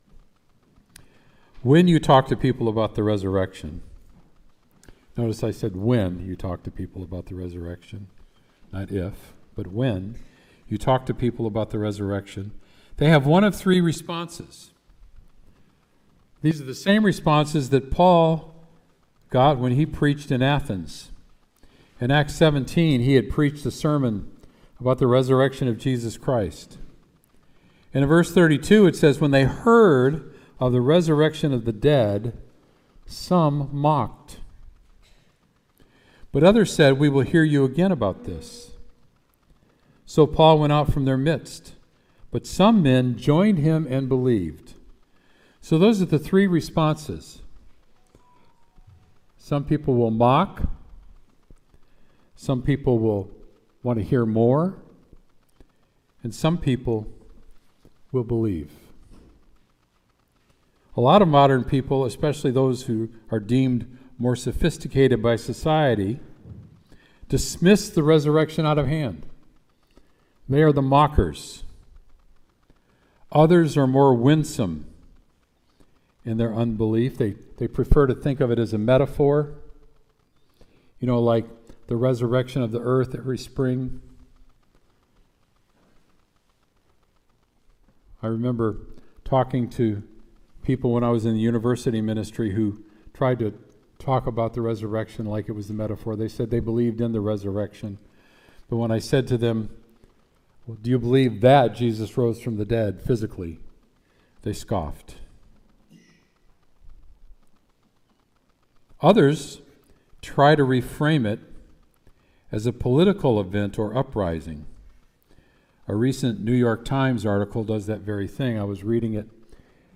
Sermon “The Resurrection”